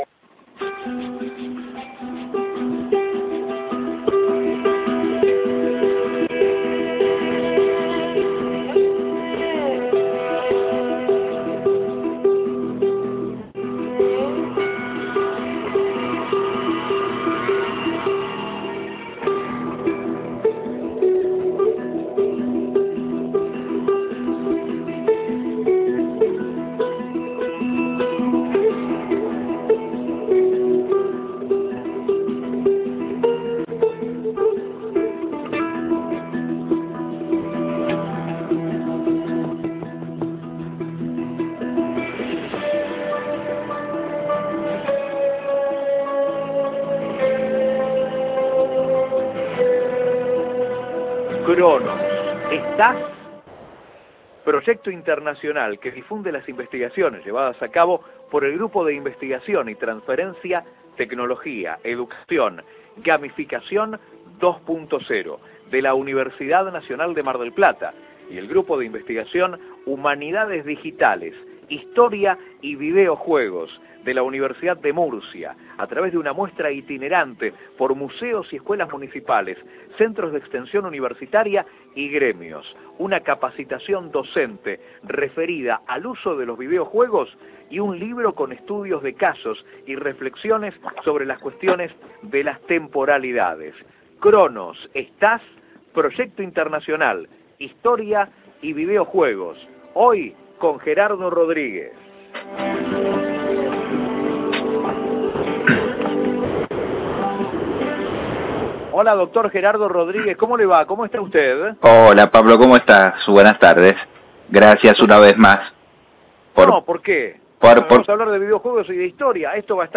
La entrevista se realizó en los contenidos del programa Enlace Universitario.
entrevista-radio-1.ogg